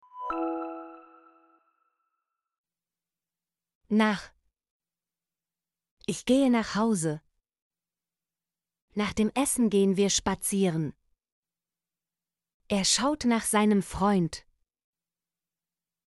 nach - Example Sentences & Pronunciation, German Frequency List